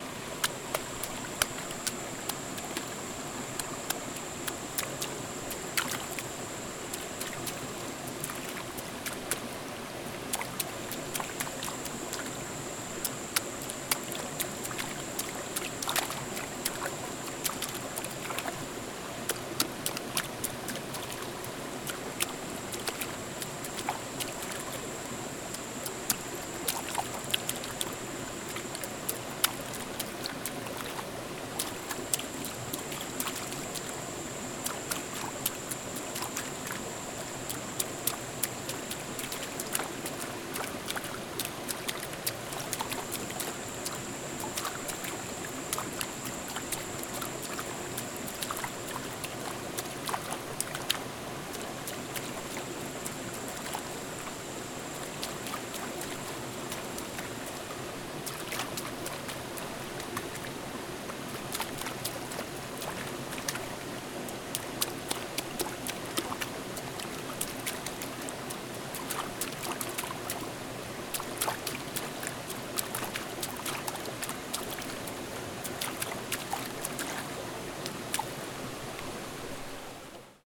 Chapoteando en el agua-vereda La Italia.mp3
Paisaje sonoro: Chapoteando en el agua en la vereda La Italia